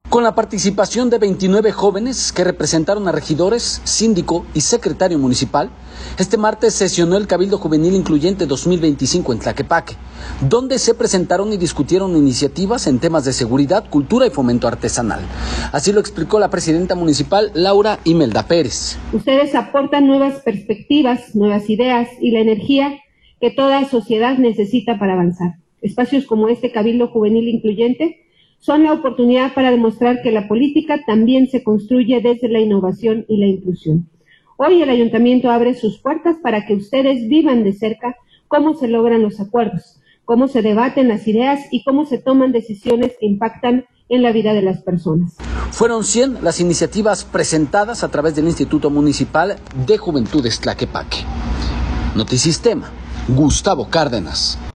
audio Con la participación de 29 jóvenes que representaron a regidores, síndico y secretario municipal, este martes sesionó el Cabildo Juvenil Incluyente 2025 en Tlaquepaque, donde se presentaron y discutieron iniciativas en temas de seguridad, cultura y fomento artesanal. Así lo explicó la presidenta municipal Laura Imelda Pérez.